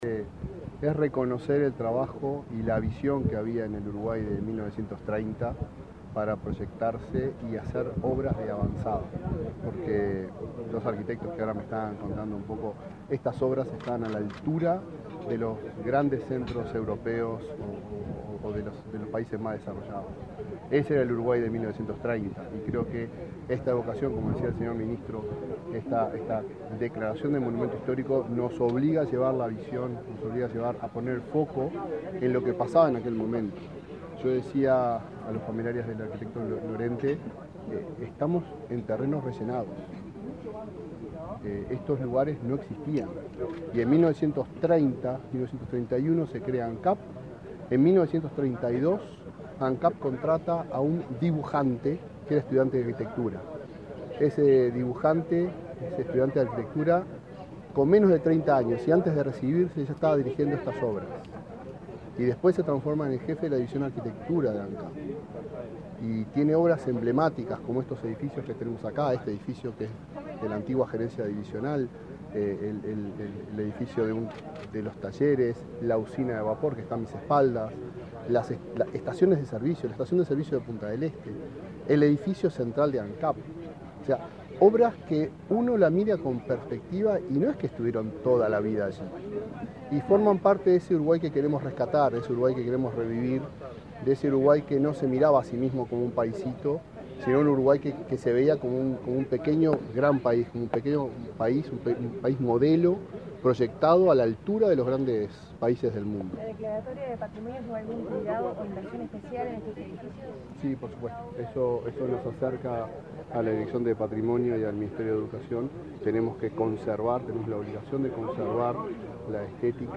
Declaraciones a la prensa del presidente de Ancap
El presidente de Ancap, Alejandro Stipanicic, participó del acto por el cual se declaró patrimonio cultural de la Nación, al conjunto de obras del